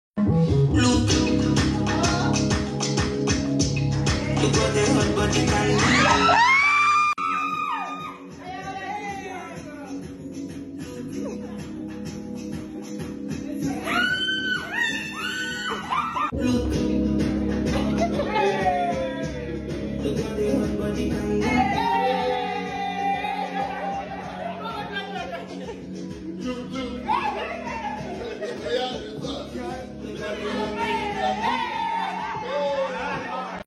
Afrobeats
high energy, confident vocals, and infectious rhythm
Over a pulsating Afrobeat-inspired production